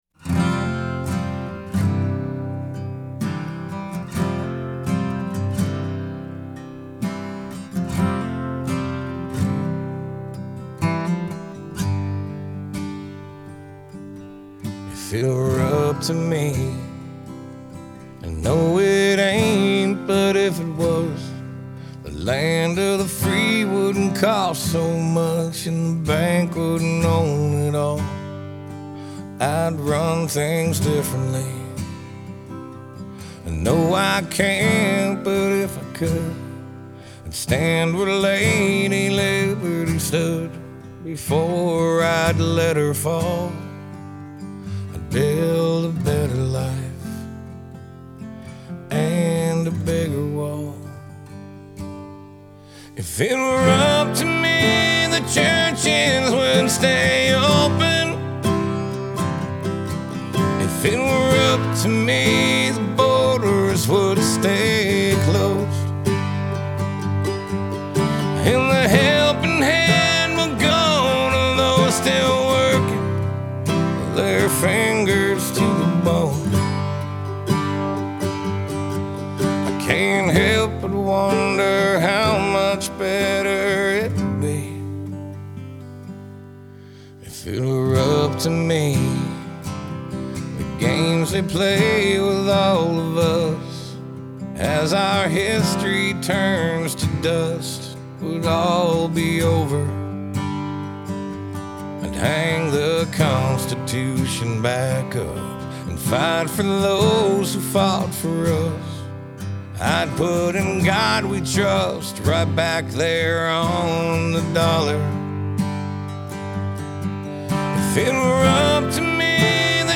Genre : Country